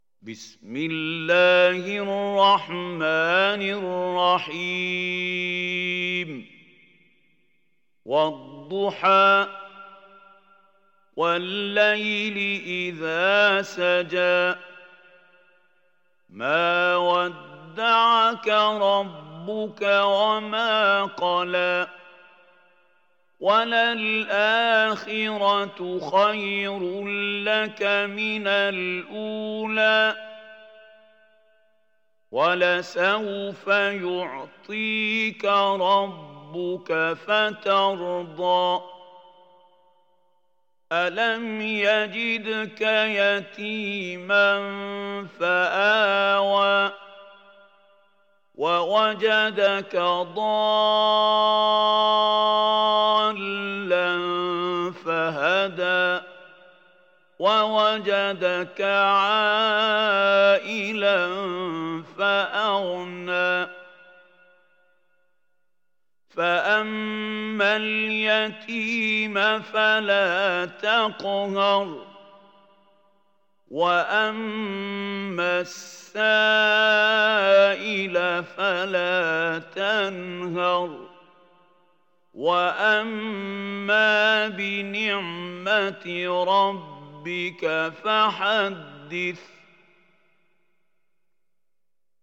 Sourate Ad Duhaa Télécharger mp3 Mahmoud Khalil Al Hussary Riwayat Hafs an Assim, Téléchargez le Coran et écoutez les liens directs complets mp3